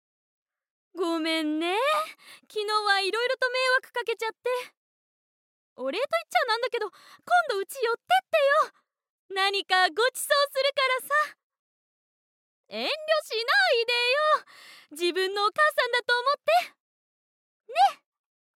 ボイスサンプル
お母さん